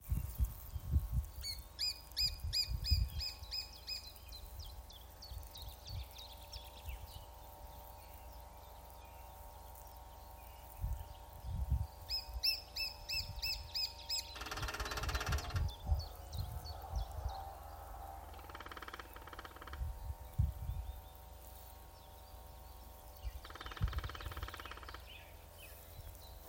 Birds -> Woodpeckers ->
Lesser Spotted Woodpecker, Dryobates minor
StatusSinging male in breeding season
NotesDzied un bungo.